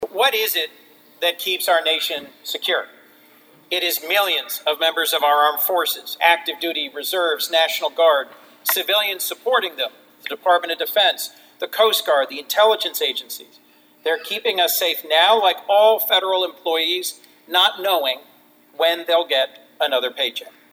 U.S. Senator Chris Coons this afternoon called for unanimous consent on the Senate floor to pass his bill that would ensure that military personnel continue to receive pay during the ongoing government shutdown…